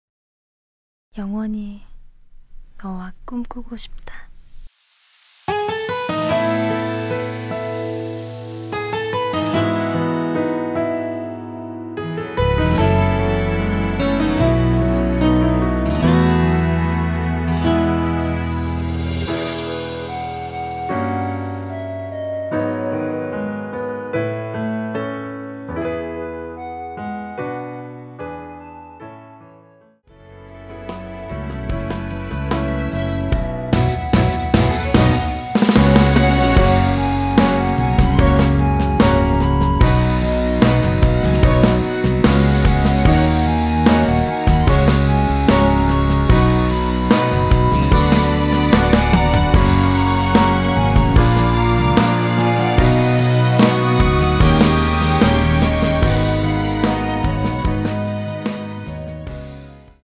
원키 멜로디 포함된 MR입니다.
시작 부분 나레이션 들어가 있습니다.(미리듣기 참조)
앞부분30초, 뒷부분30초씩 편집해서 올려 드리고 있습니다.